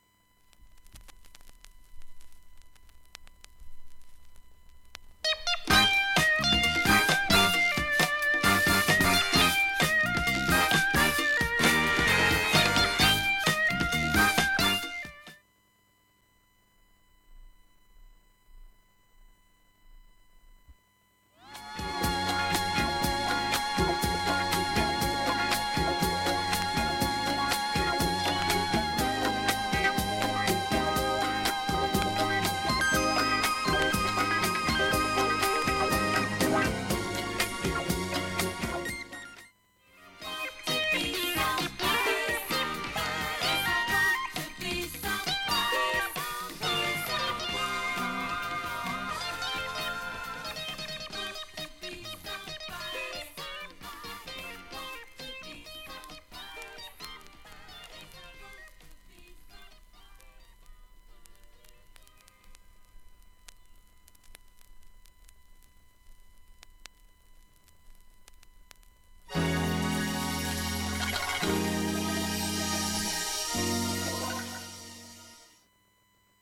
音質良好全曲試聴済み。
B-2序盤小さい点スレでわずかなプツ６回出ます。